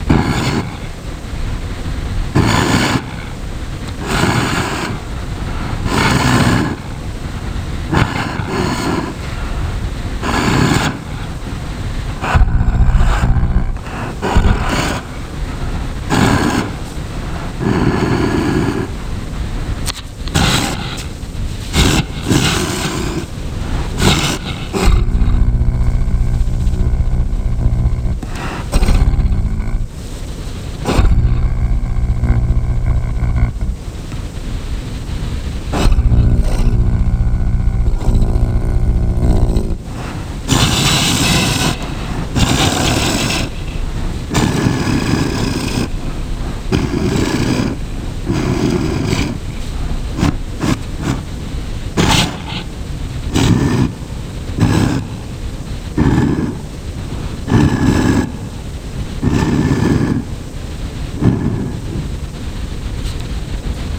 File:Full Cronus Roar Set.wav
Full_Cronus_Roar_Set.wav